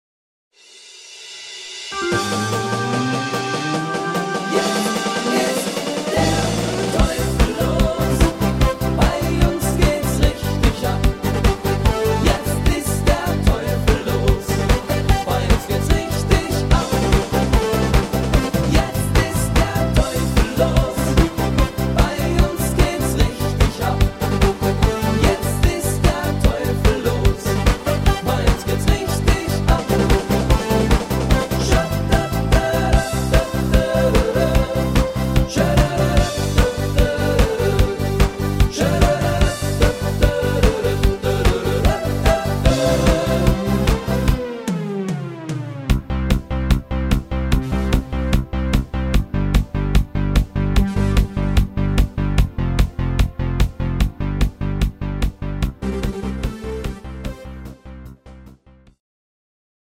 Extreme Party Mix